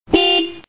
・ホーン追加
肝心の音ですが、こんな感じになりました。